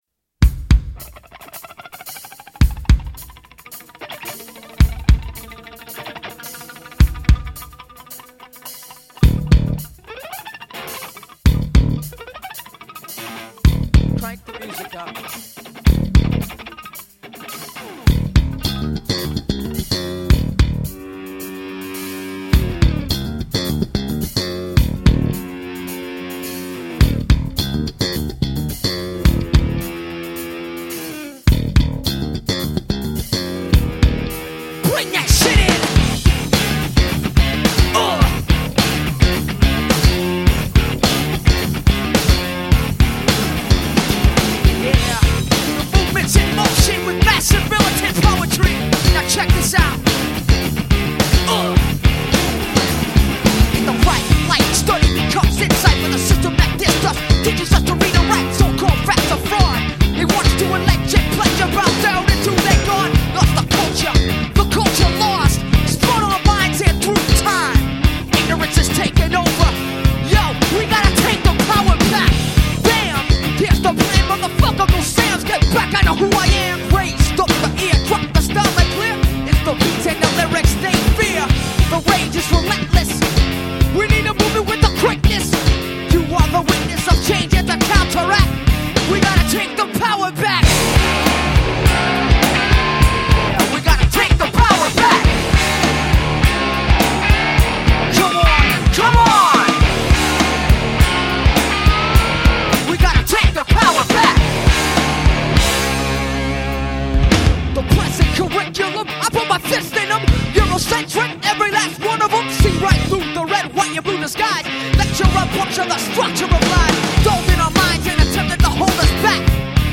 Hard and fast paced. ft.